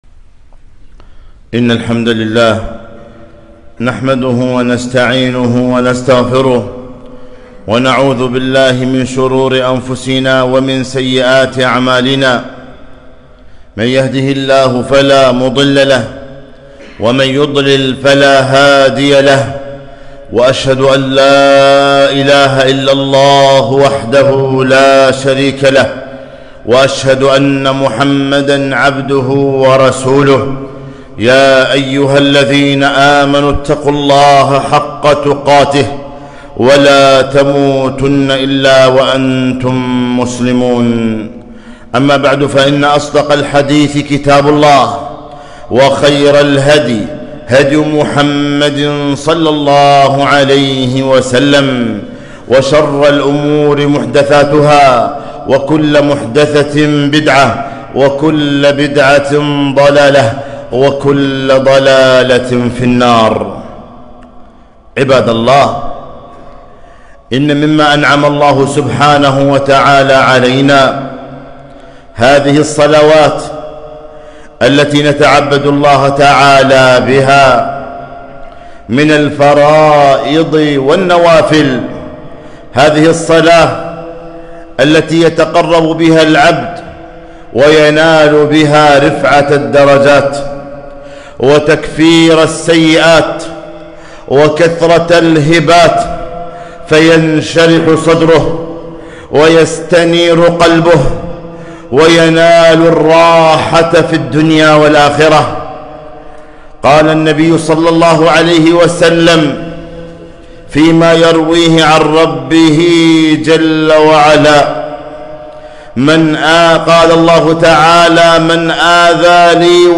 خطبة - تقربوا إلى الله بالنوافل